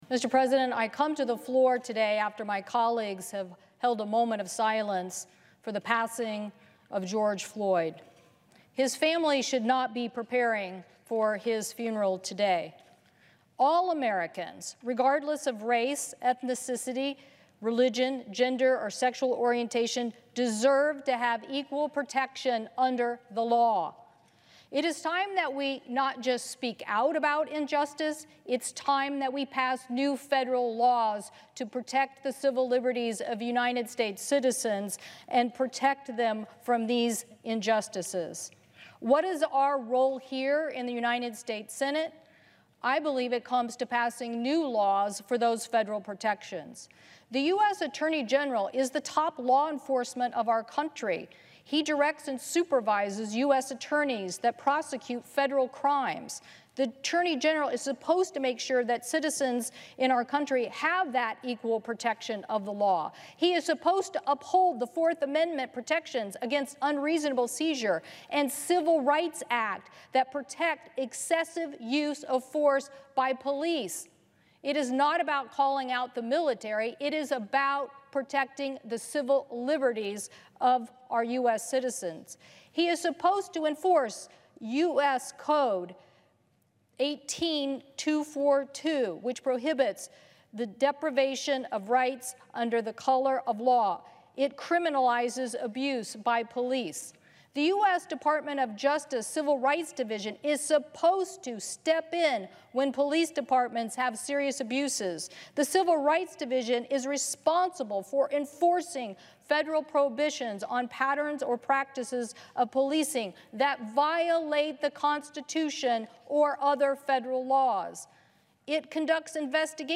civil-rights-policing-floor-speech-audio&download=1